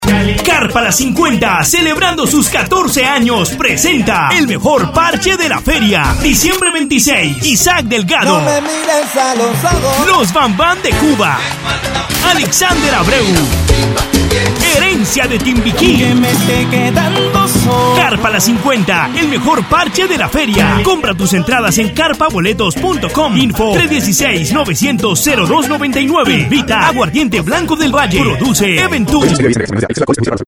Voz comercial para radio